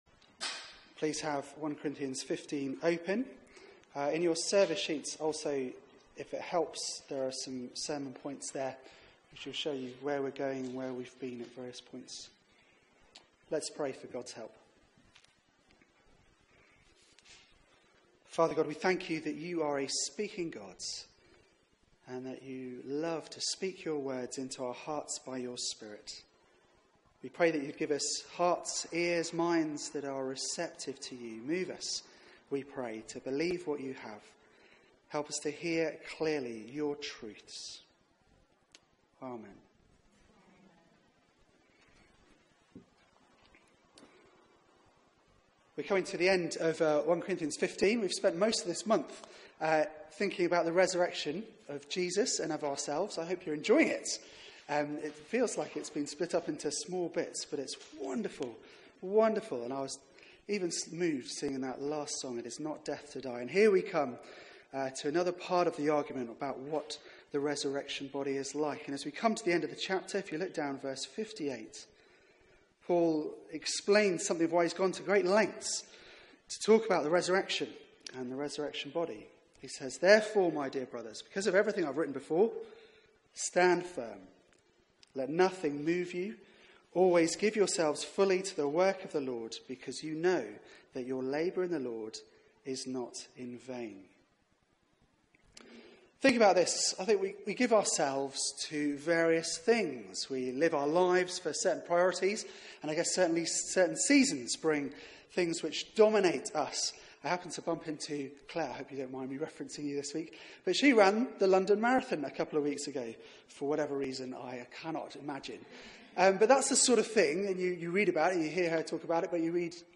Media for 4pm Service on Sun 07th May 2017 16:00 Speaker
Series: A Church with Issues Theme: Death defeated Sermon